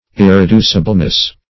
irreducibleness - definition of irreducibleness - synonyms, pronunciation, spelling from Free Dictionary
-- Ir`re*du"ci*ble*ness, n. --